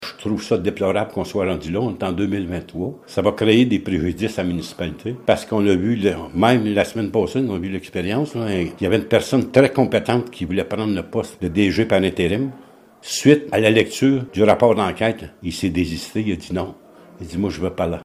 Le maire de Blue Sea, Laurent Fortin, explique que ces événements portent préjudice à la Municipalité dans le recrutement de personnel: